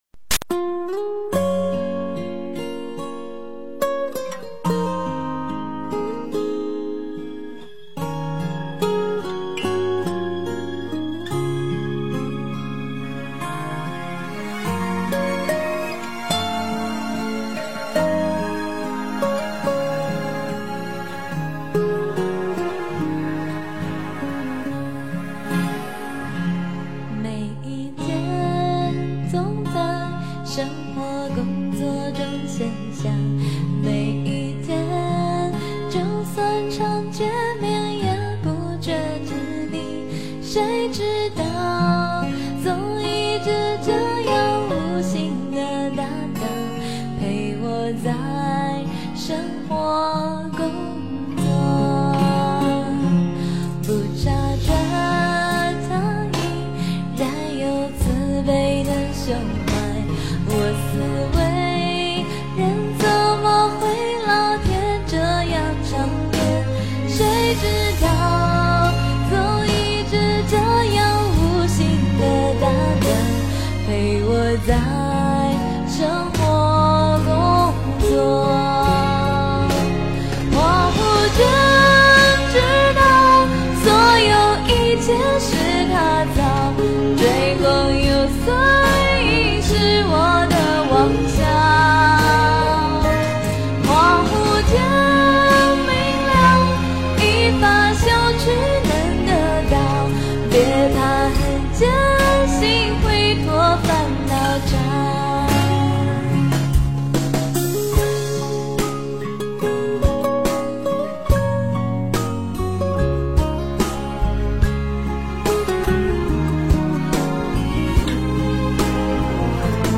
无形的大道 诵经 无形的大道--佛教音乐 点我： 标签: 佛音 诵经 佛教音乐 返回列表 上一篇： 无边 下一篇： 喜欢佛的圣号 相关文章 佛说阿弥陀三耶三佛萨楼佛檀过度人道经B 佛说阿弥陀三耶三佛萨楼佛檀过度人道经B--风柯月渚...